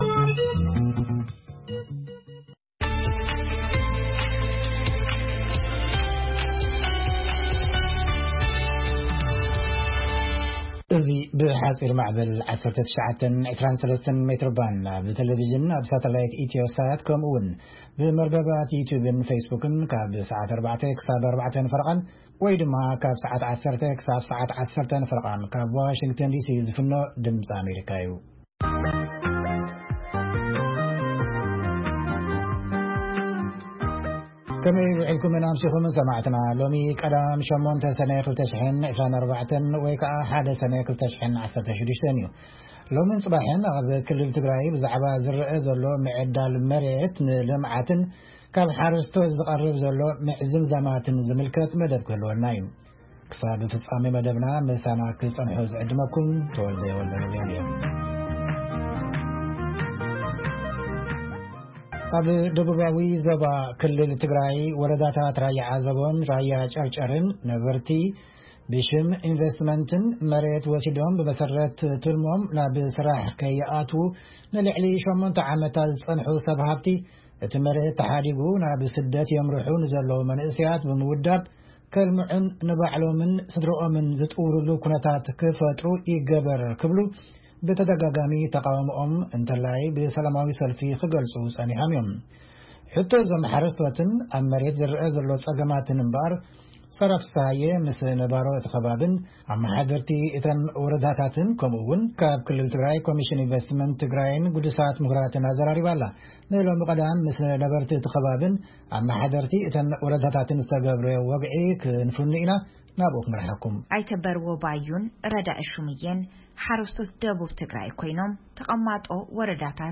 ድምጺ ኣሜሪካ ፈነወ ቛንቛ ትግርኛ ካብ ሶኑይ ክሳብ ሰንበት ይፍነው። ፈነወ ቛንቛ ትግርኛ ካብ ሶኑይ ክሳብ ዓርቢ ብዕለታዊ ዜና ይጅምር፥ እዋናዊ ጉዳያትን ሰሙናዊ መደባት'ውን የጠቓልል ።ቀዳምን ሰንበትን ኣብቲ ሰሙን ዝተፈነው መደባት ብምድጋም ፈነወ ቛንቛ ትግርኛ ይኻየድ።